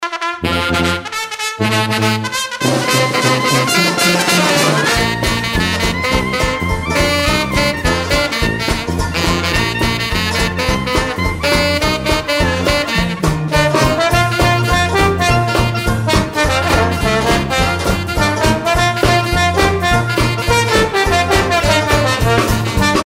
Versions "Jazz"